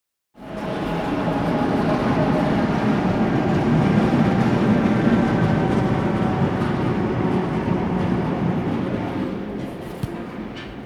I rumori di ferraglia che vengono dal passato
Queste sono delle registrazione effettuate da un appartamento al sesto piano di un palazzo che si affaccia sulla ferrovia alle 22,50 e alle 23 del 24/06/2015 : immaginate cosa si possa sentire nei piani inferiori !